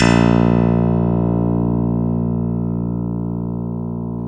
Index of /90_sSampleCDs/Roland - Rhythm Section/KEY_Pop Pianos 1/KEY_Pop Pianos